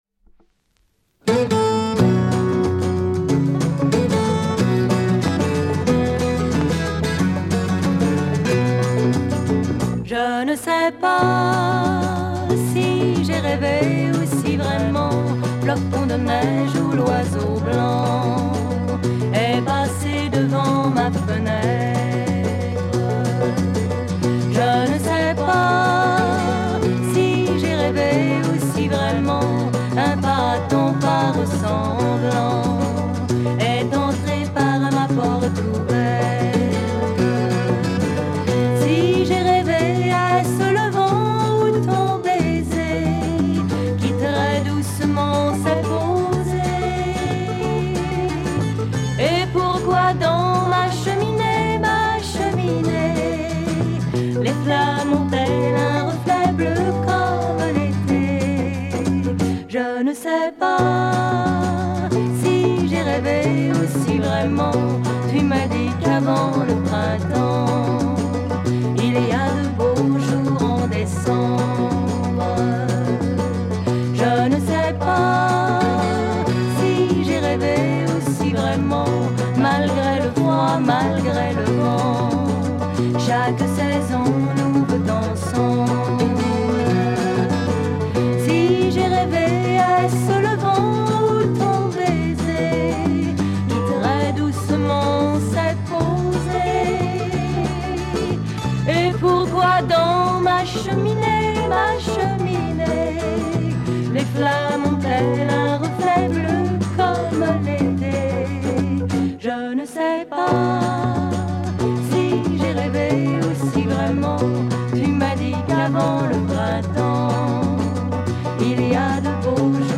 French female Pop chanson single